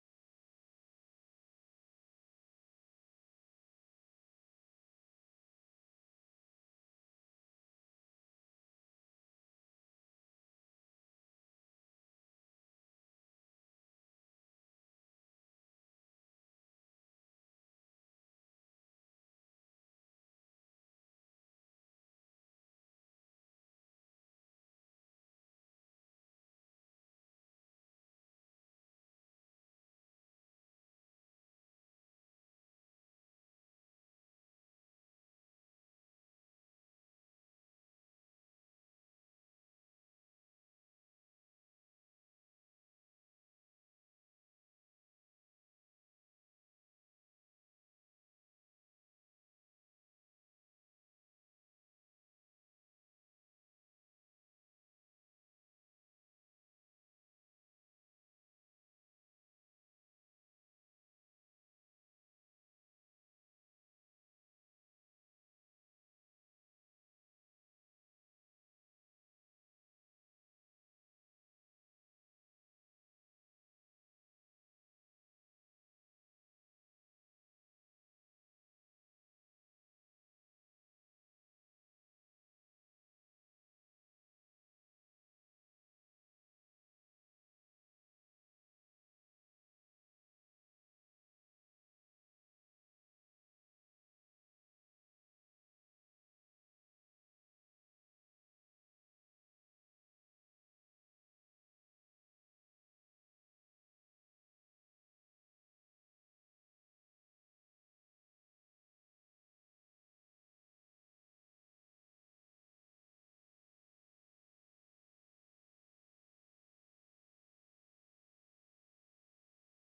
Tutorial on the Probabilistic Aggregate Consumer Exposure Model, PACEM for short.